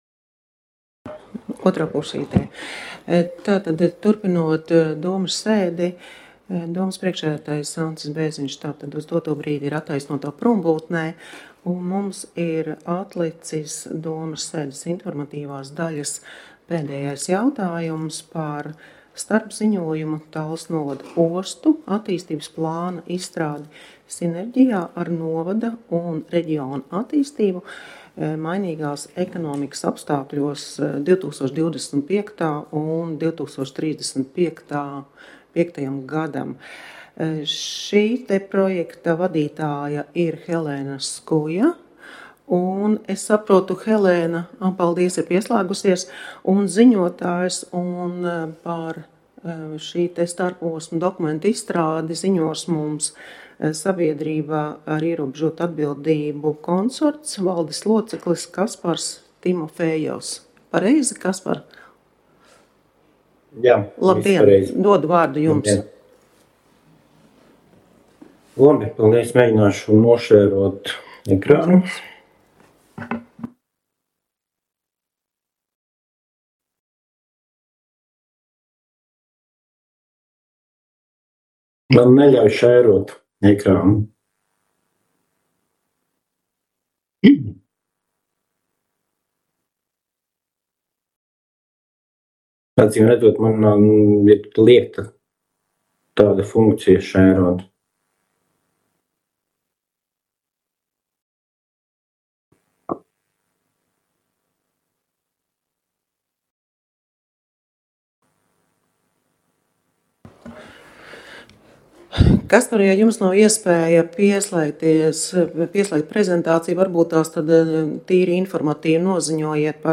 Talsu novada domes sēde Nr. 8